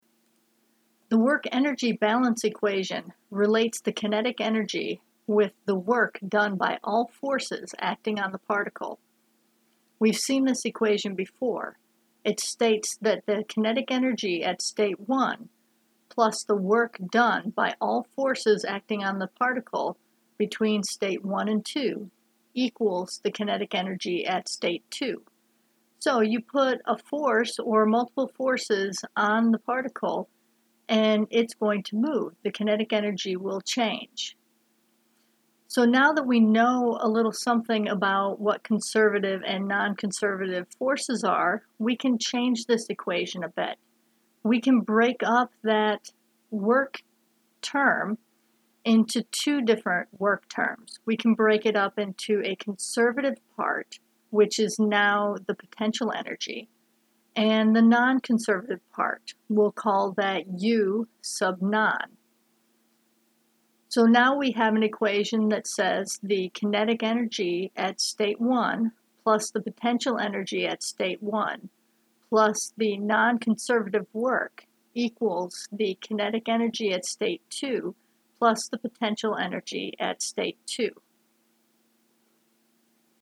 Lecture content